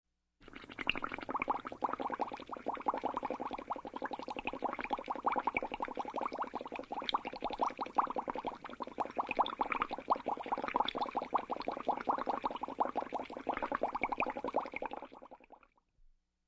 Вода звуки скачать, слушать онлайн ✔в хорошем качестве